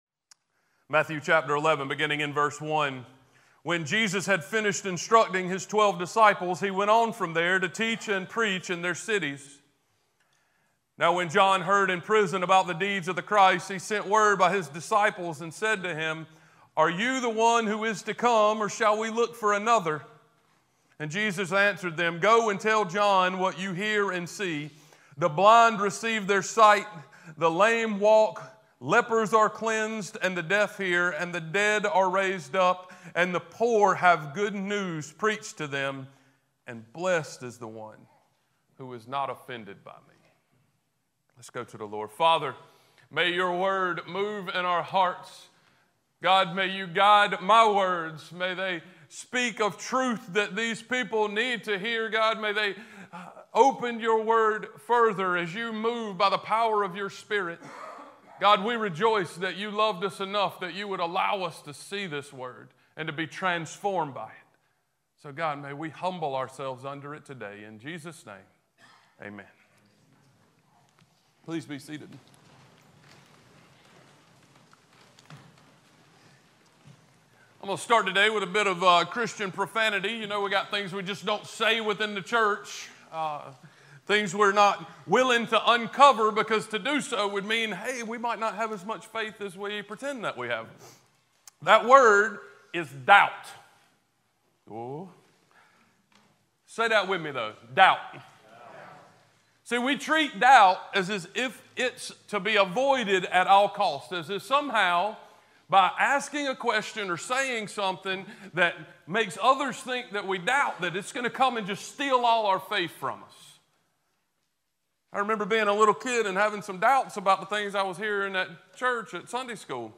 Passage: Matthew 11:1-6 Service Type: Sunday Worship